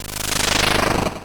snd_puzzle_start.wav